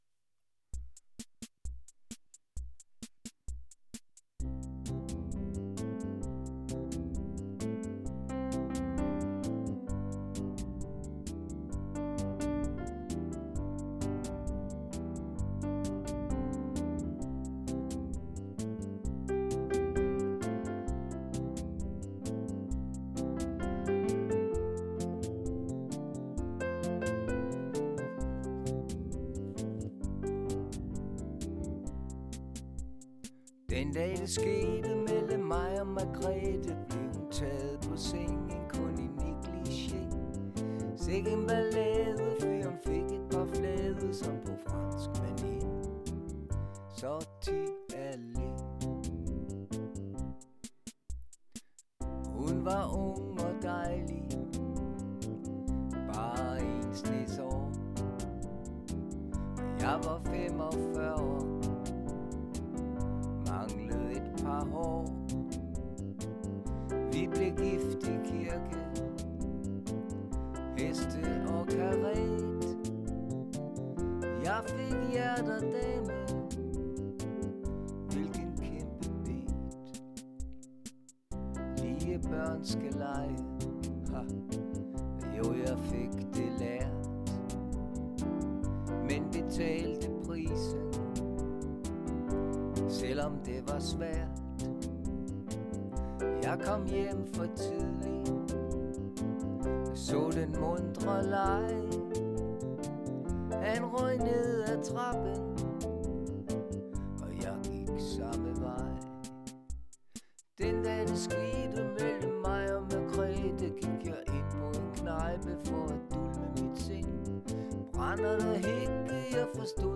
med Sang